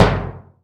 Index of /90_sSampleCDs/AKAI S6000 CD-ROM - Volume 5/Brazil/SURDO
SI SURDO 2.WAV